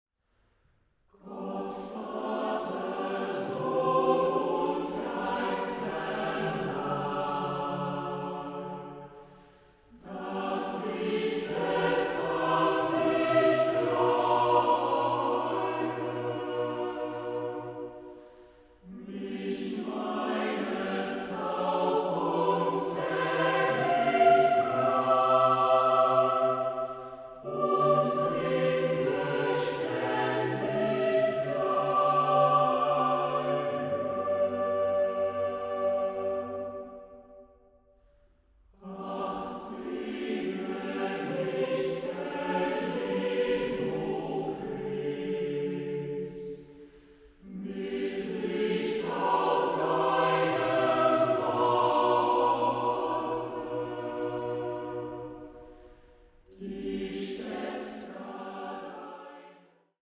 Chamber choir